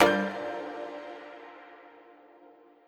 menu-freeplay-click.wav